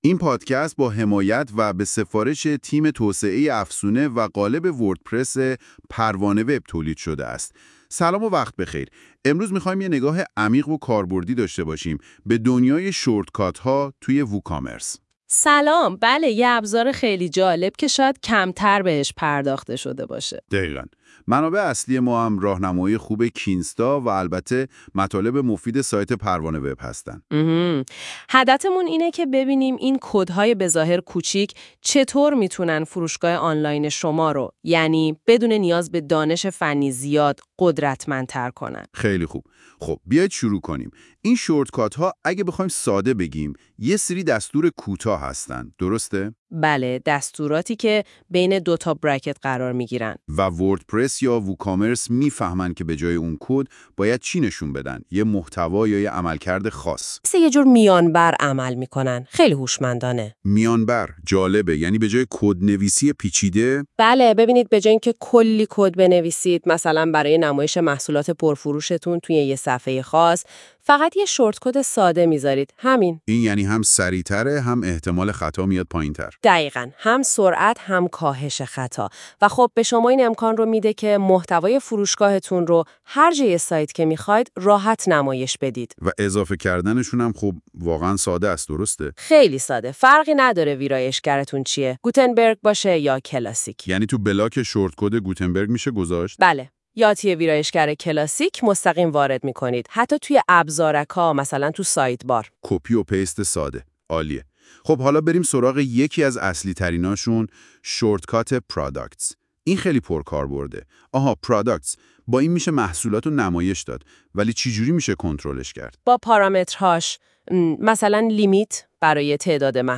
خلاصه صوتی و چیت شیت(Cheat Sheet) کدهای کوتاه ووکامرس
قبل از شروع مقاله ، اگه حوصله یا فرصت مطالعه این مقاله رو ندارید ، پیشنهاد می کنیم پادکست صوتی زیر که با ابزار هوش مصنوعی (گوگل notebooklm ) به زبان فارسی توسط تیم پروان وب تولید شده است ، گوش کنید.